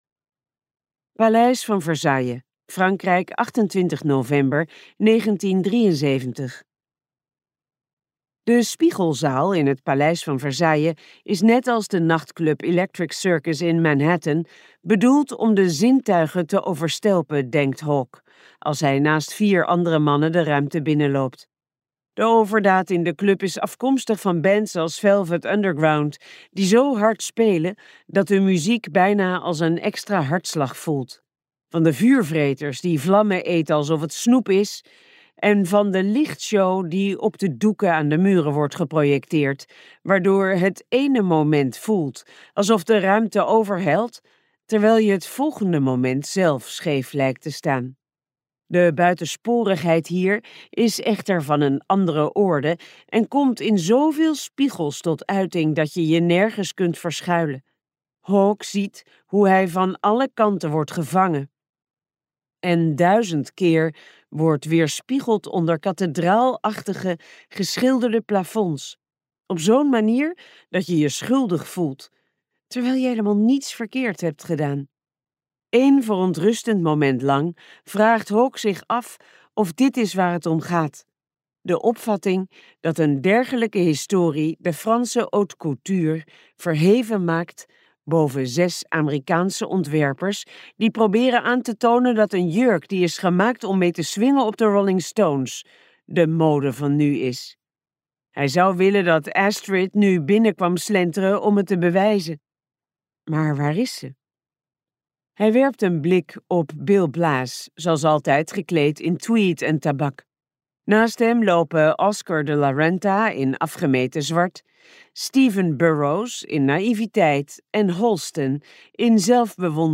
Uitgeverij De Fontein | De verdwijning van astrid bricard luisterboek